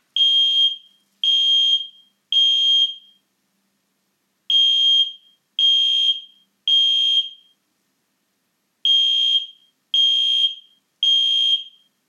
• Ei208D Alarm:
ei208d-kohlenmonoxidmelder-alarm.mp3